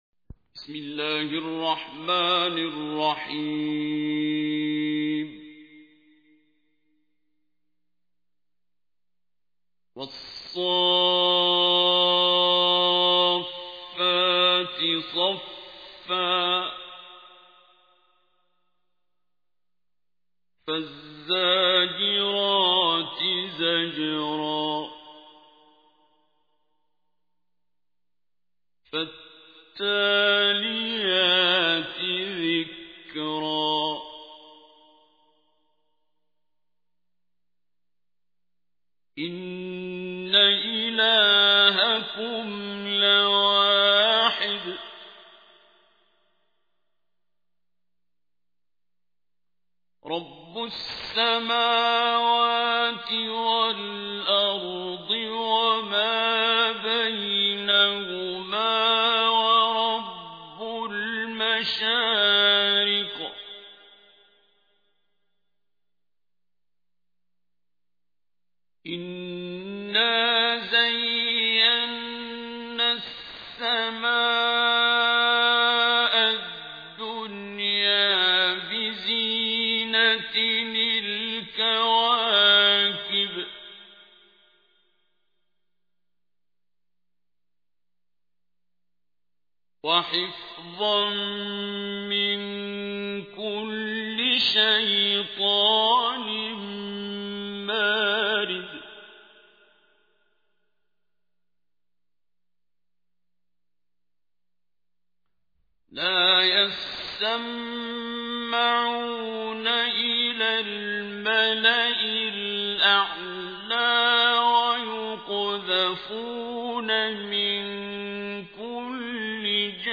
تحميل : 37. سورة الصافات / القارئ عبد الباسط عبد الصمد / القرآن الكريم / موقع يا حسين